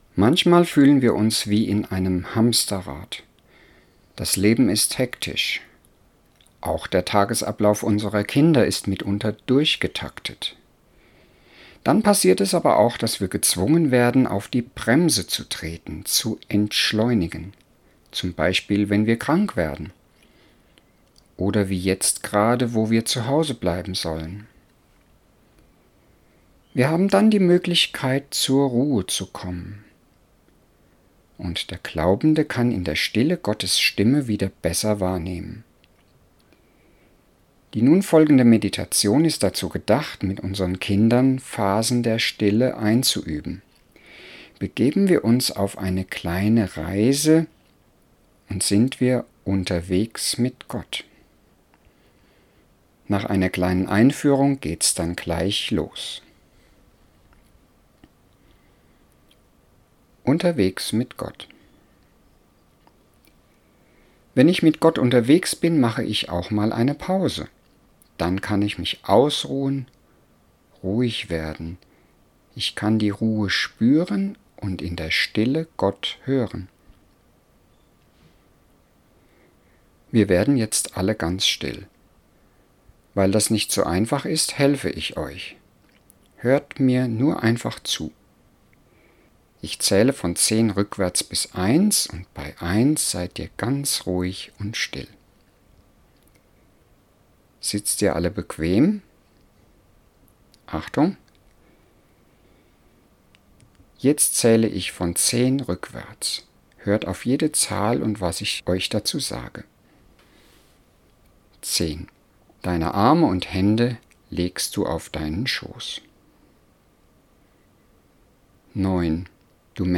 • Meditation mit Kindern